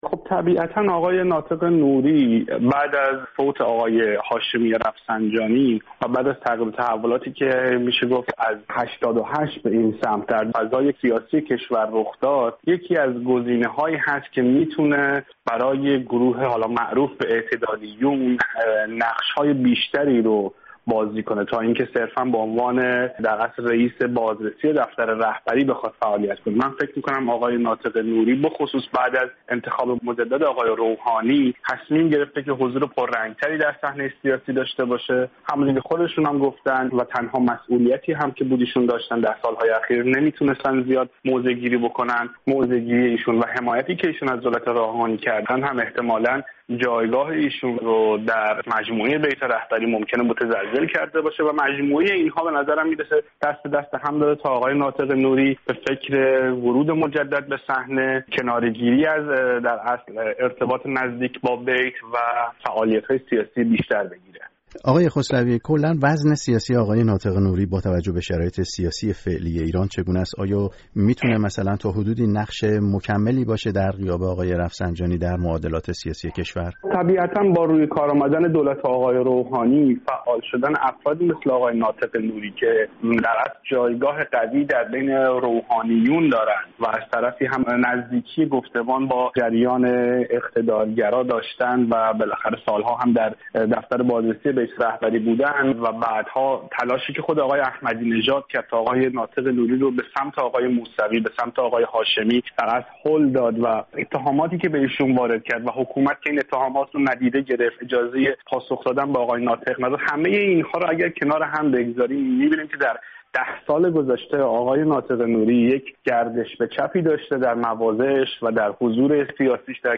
تحلیل‌گر مسائل سیاسی ایران در ایتالیا